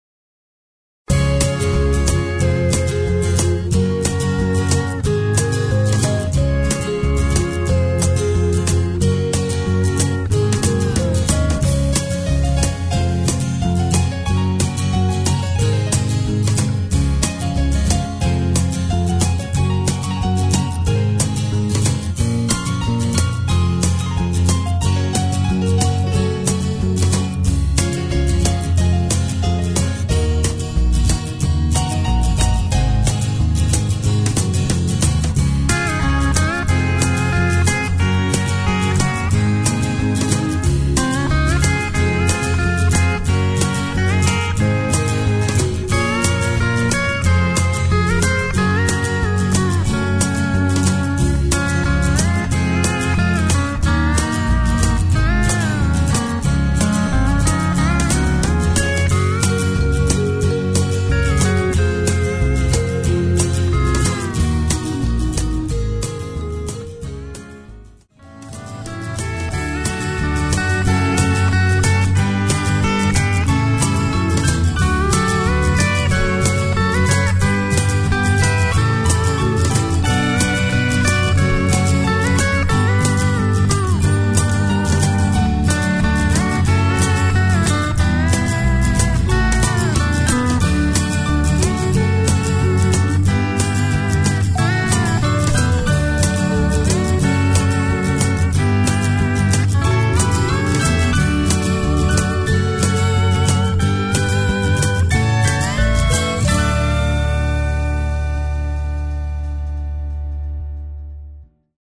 Instrumental TRacks
Sing Along Version